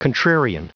con·trar·i·an \kən-ˈtrer-ē-ən, kän-\